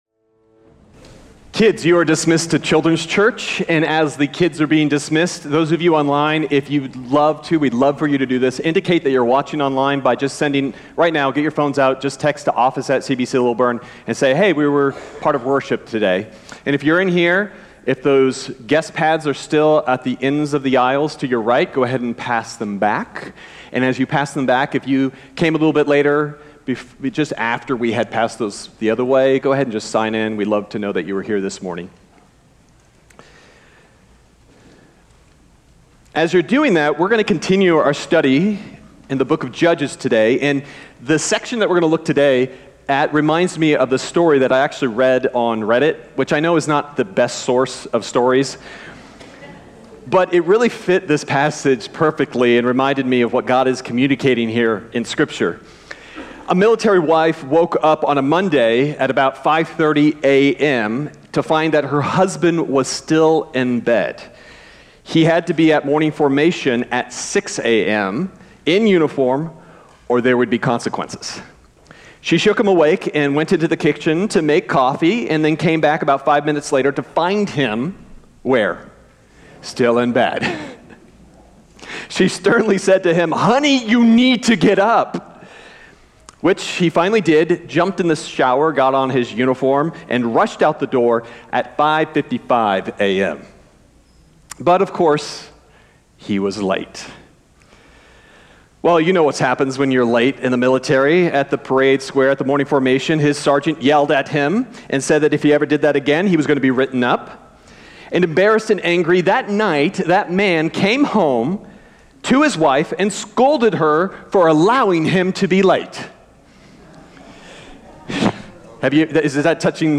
February_9th_Sermon_Audio.mp3